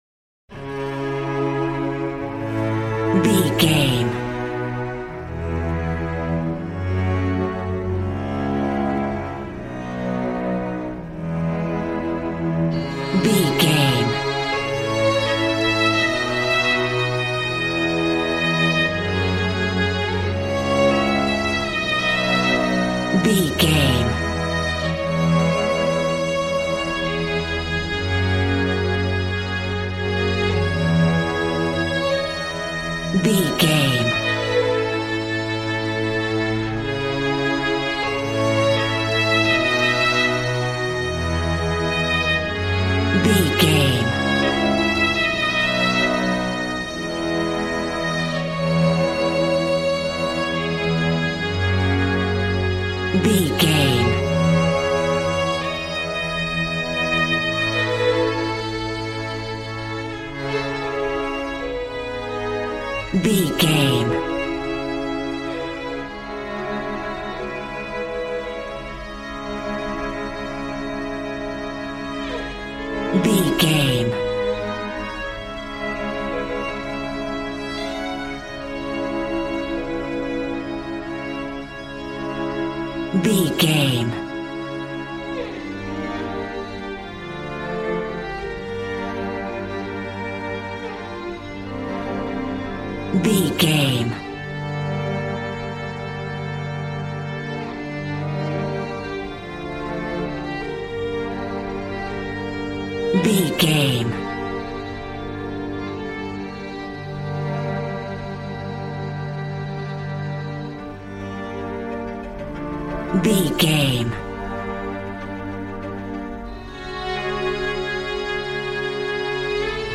Beautiful stunning solo string arrangements.
Regal and romantic, a classy piece of classical music.
Ionian/Major
regal
brass